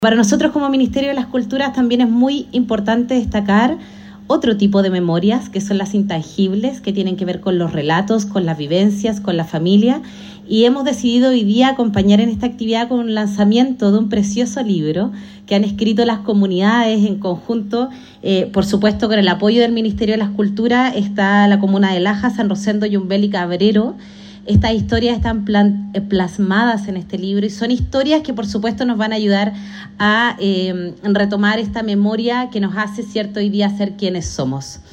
En esta línea, la seremi de las Culturas, las Artes y Patrimonio, Paloma Zúñiga, abordó la relevancia de esta publicación.